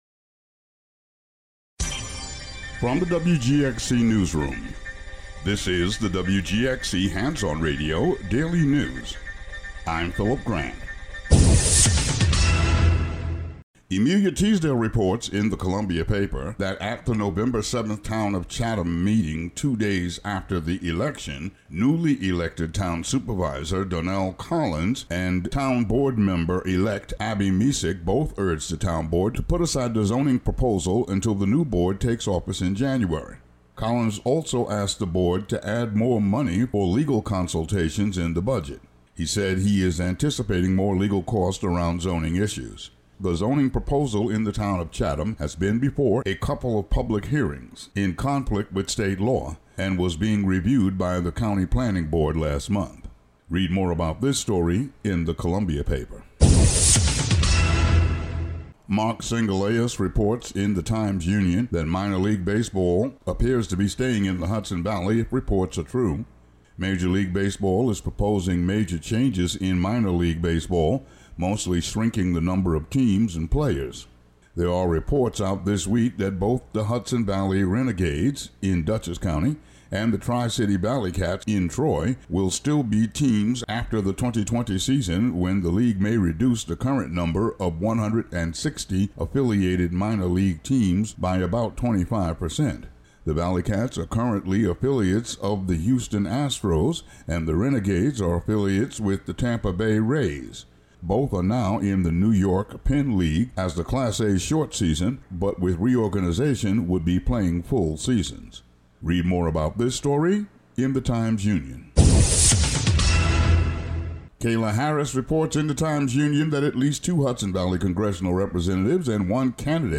The audio version of the local news for Mon., Nov. 18.